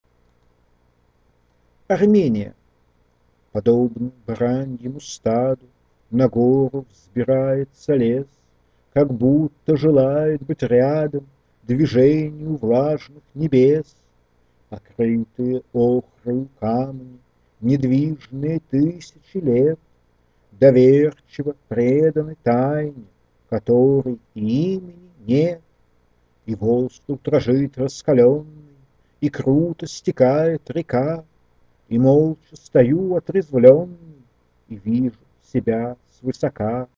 Поэтому я записал их в аудиофайлы. Я говорю с эпохою ровным голосом – воплей много и без меня.